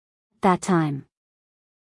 at-that-time-us-female.mp3